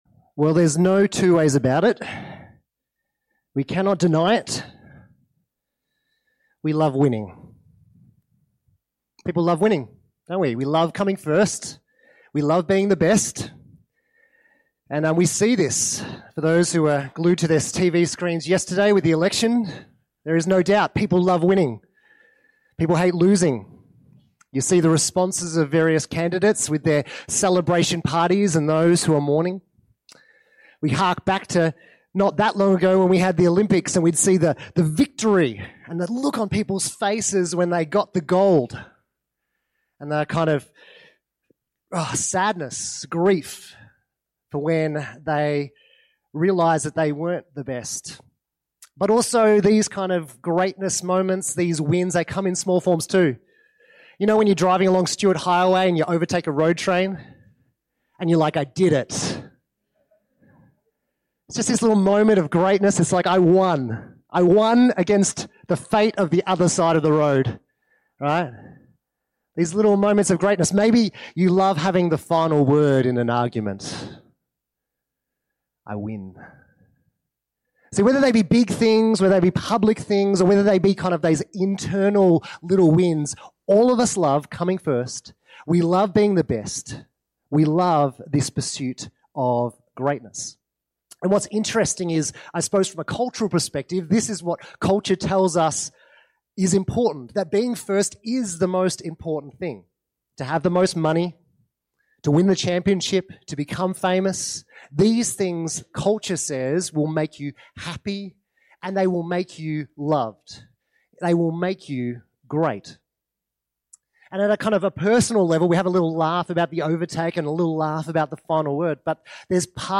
Sermons – Alice Springs Baptist Church